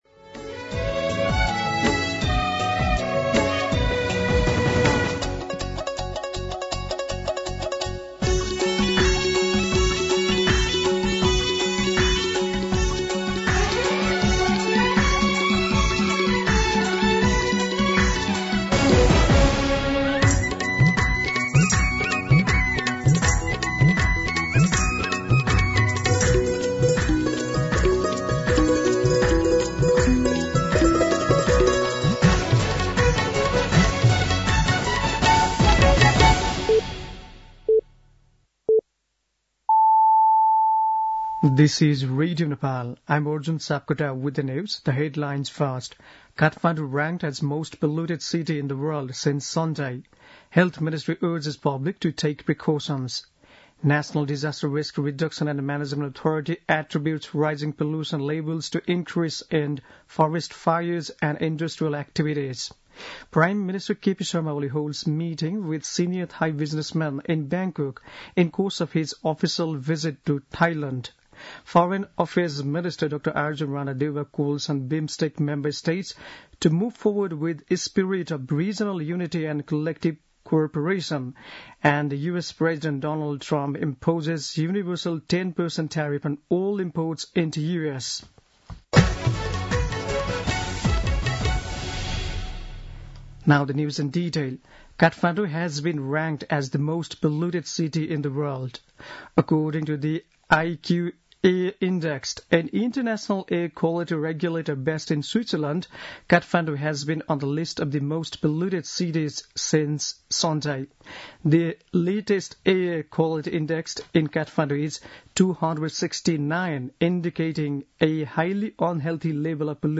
दिउँसो २ बजेको अङ्ग्रेजी समाचार : २१ चैत , २०८१
2-pm-English-News.mp3